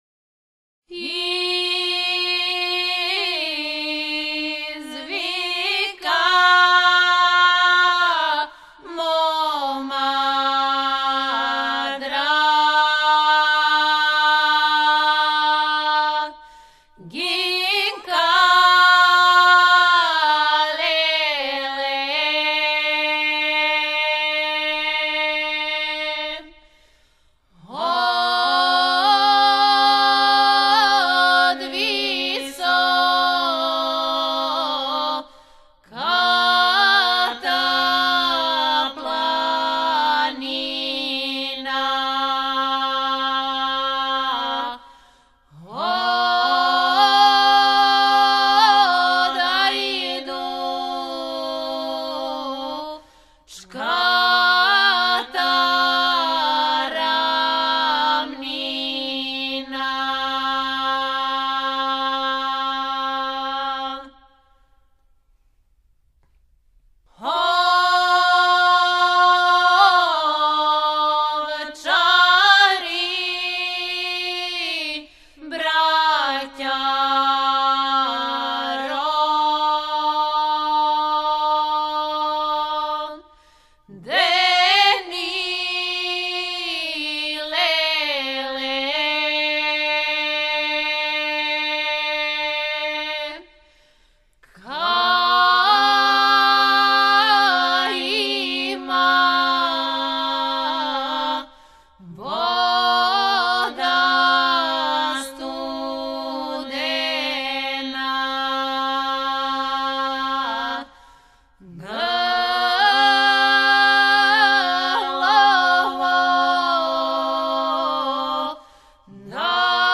Порекло песме: Македонија Начин певања: ? Напомена: Песма родољубивог садржаја, потиче из српског села Кучково код Скопља.